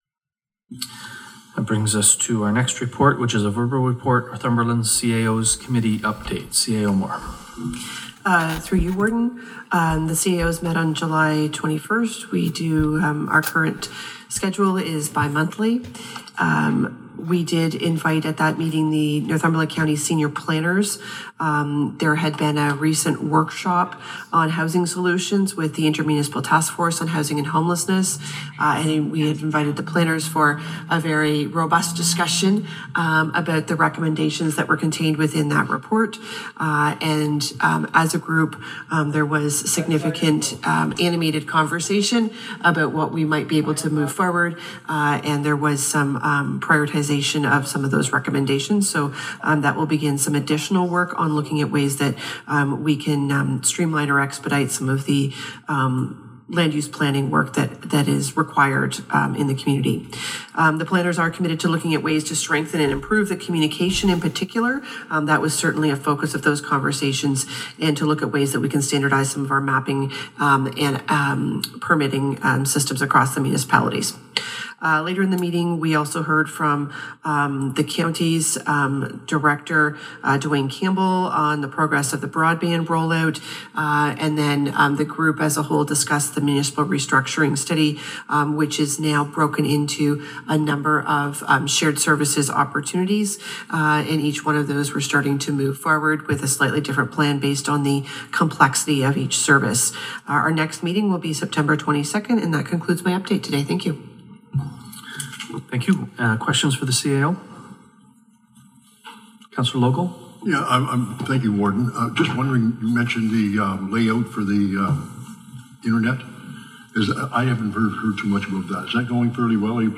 Crate and Port Hope Mayor Olena Hankivsky expressed concerns over damage to roads and culverts at the Aug. 13 regular county council meeting.
In Their Words is a feature that allows people to listen to what is going on at the various councils within Northumberland.